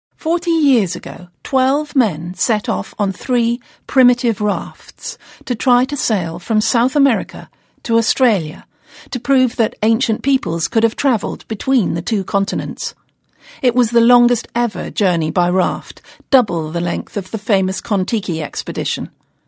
【英音模仿秀】纵一苇之所如，凌万顷之茫然 听力文件下载—在线英语听力室